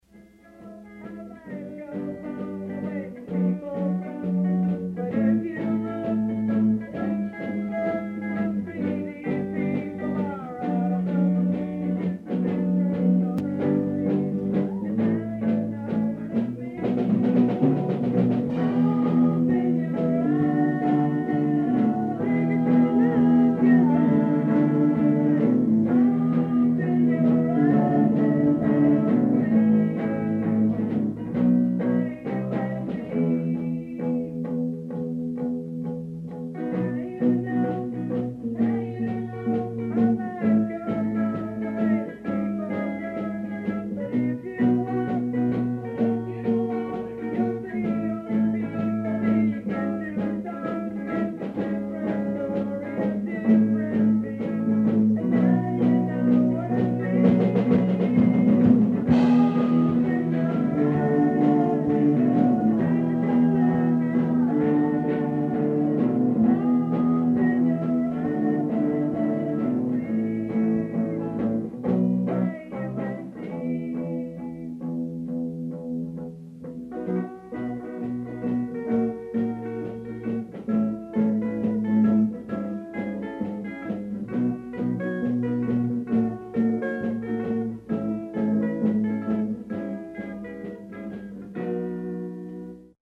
Lead Vocal, Lead Guitar
Drums
Bass, Back Vocal
Guitar, Back Vocal